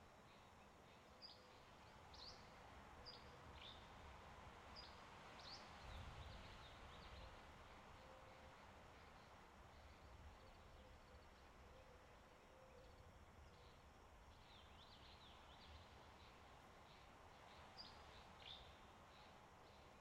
sfx_amb_map_zoomedin_hills.ogg